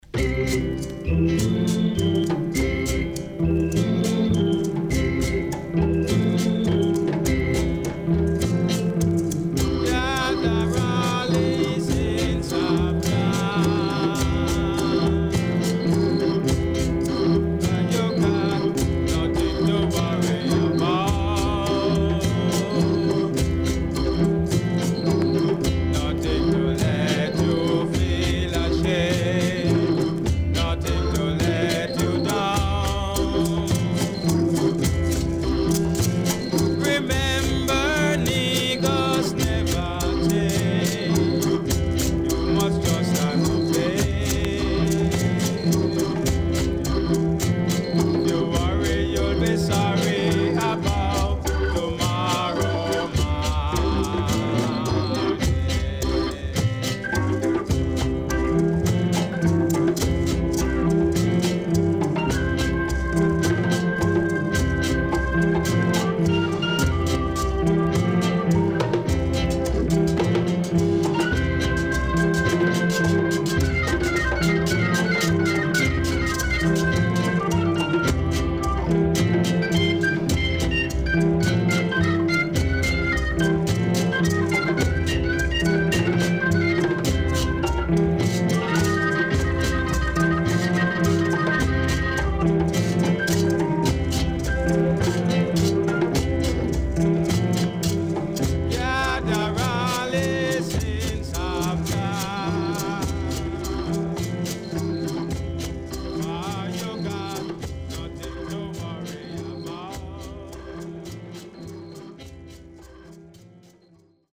HOME > REGGAE / ROOTS
Mega Rare.Killer Nyahbingi
SIDE A:.少しチリノイズ入ります。